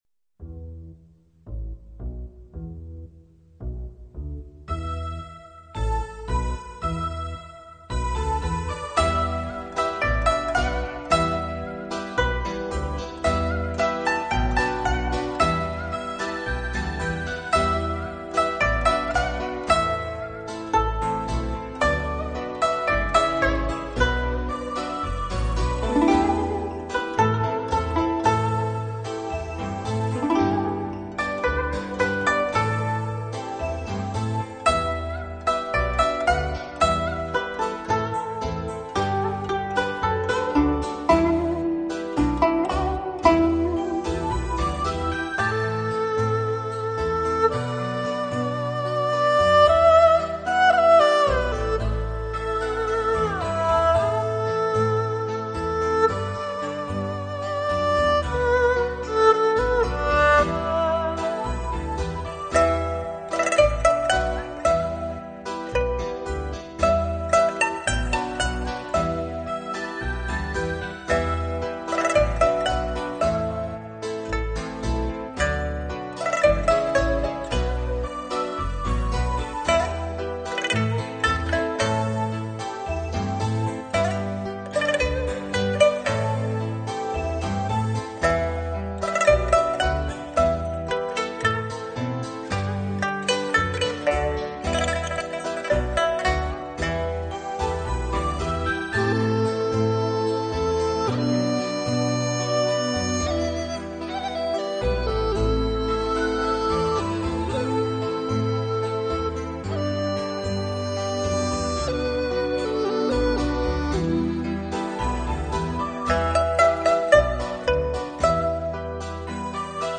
在音樂中呈現出一種和諧、自在的韻律與意境，給予大腦一種柔軟、